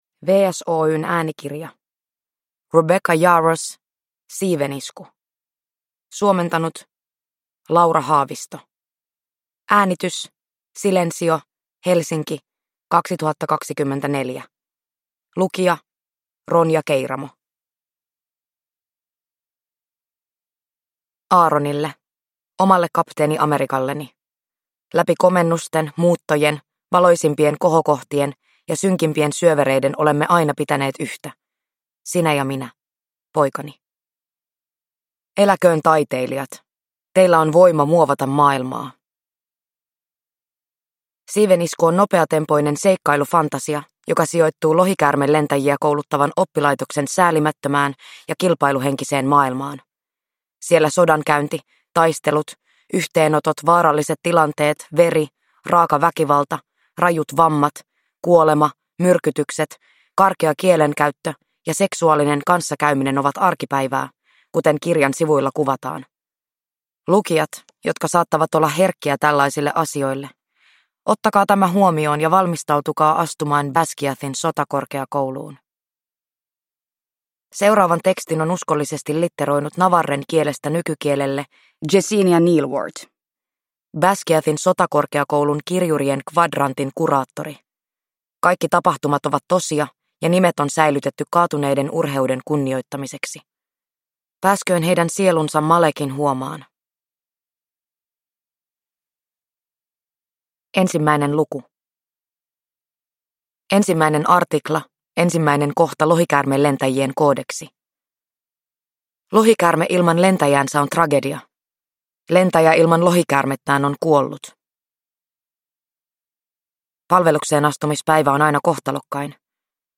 Siivenisku – Ljudbok